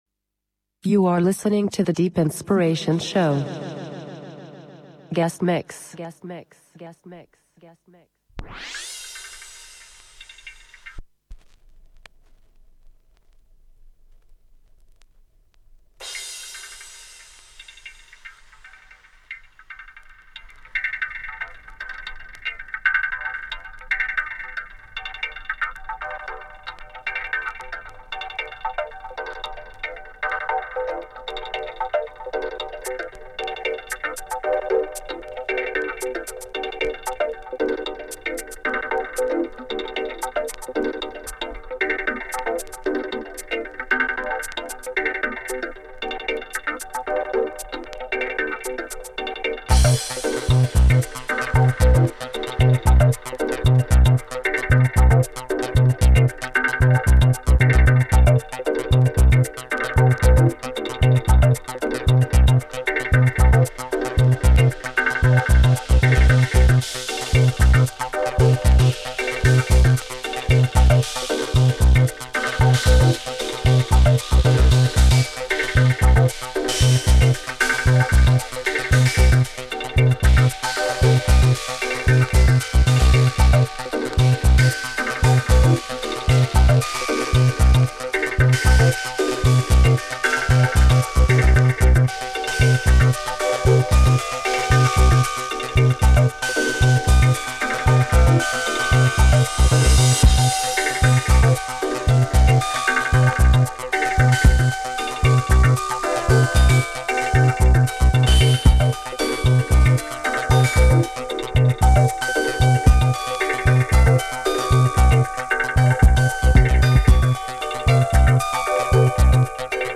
mix
Great "vinyl only" selection